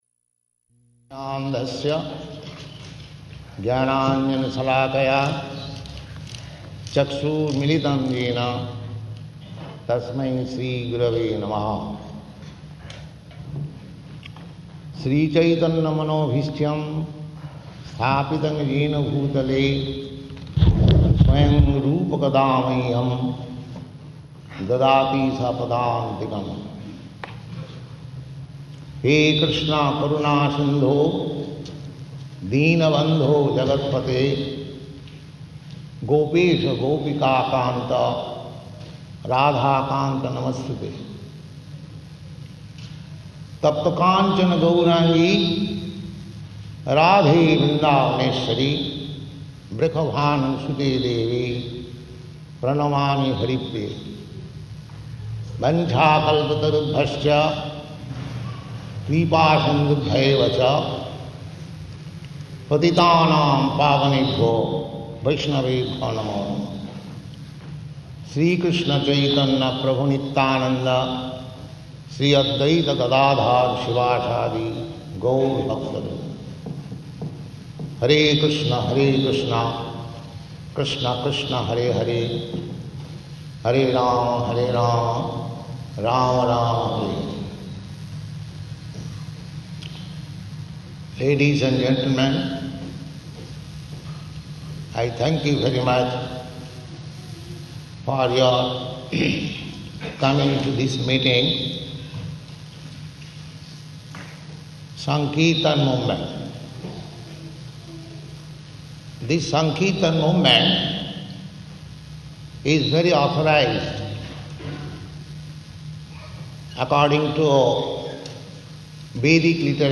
Type: Lectures and Addresses
Location: London
[chants maṅgalācaraṇa prayers]